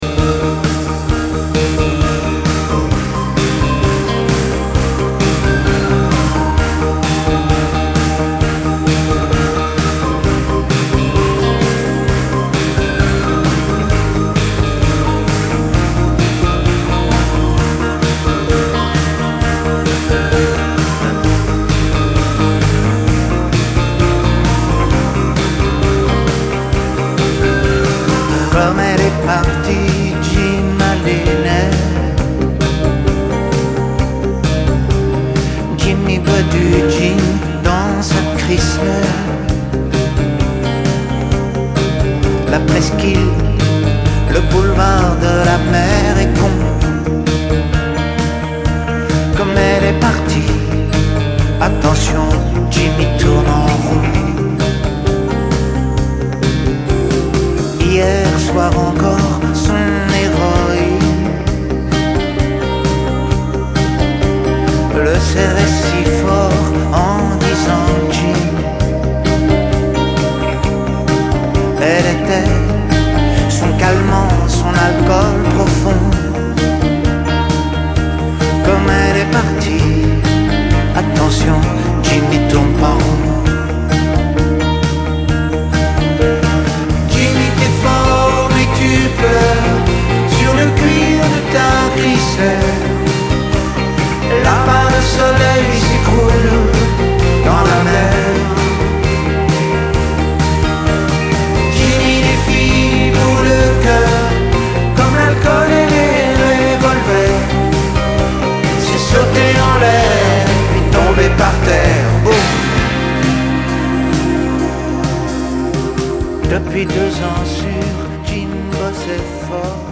tonalité MIb majeur